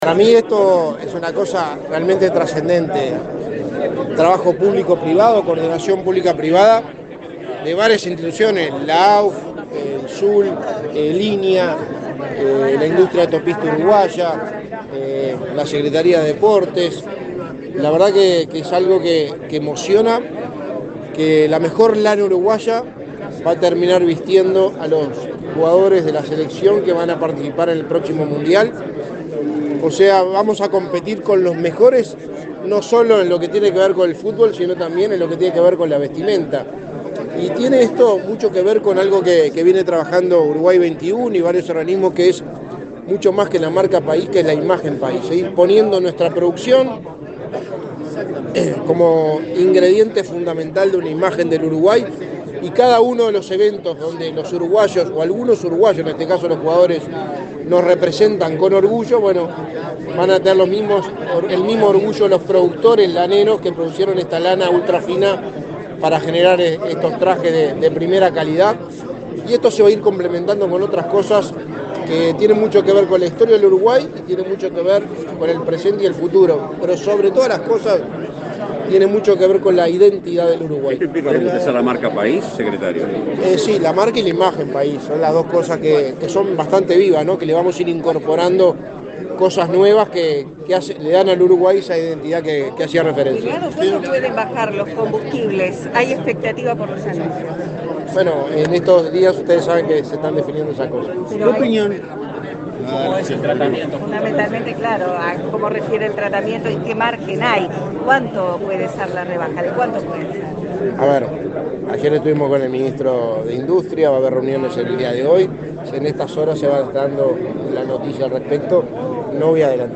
Declaraciones del secretario de Presidencia, Álvaro Delgado
Luego, dialogó con la prensa.